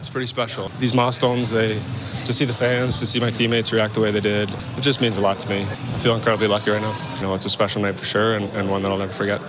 An emotional McDavid spoke to reporters following the game.